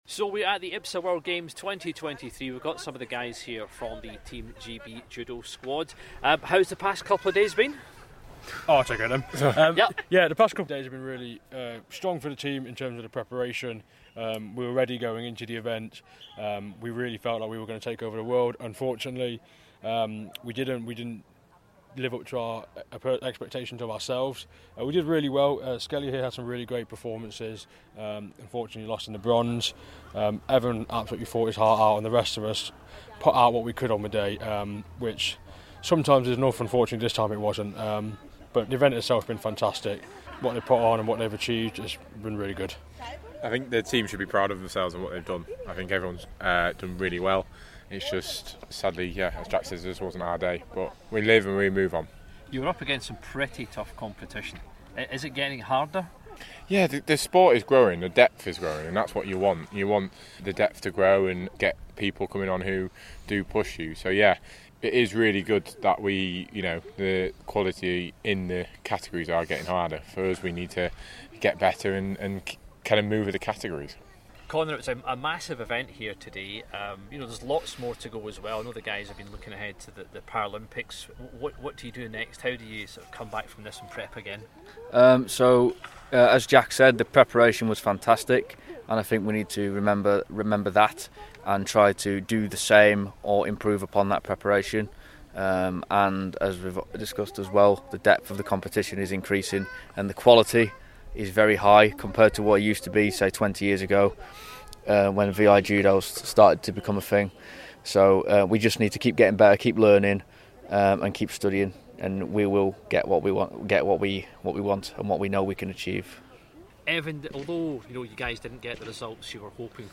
spoke to the squad to find out how they got on.